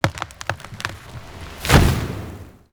tree.wav